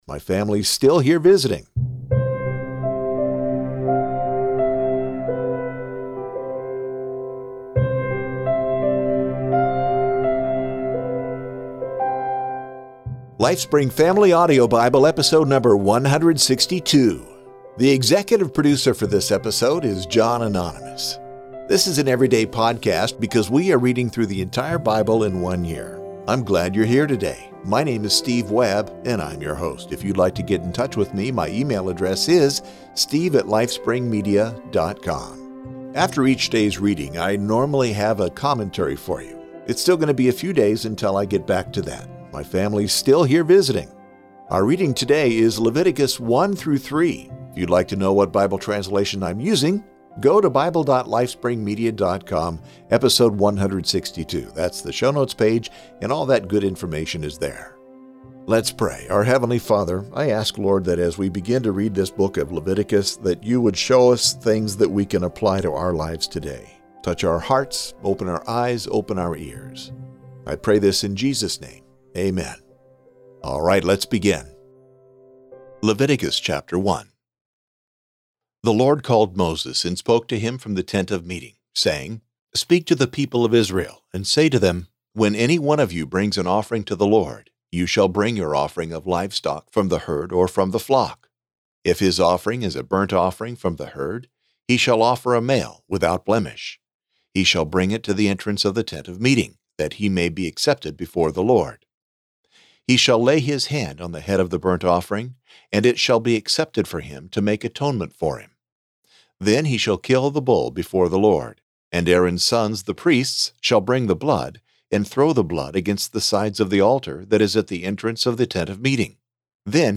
bible reading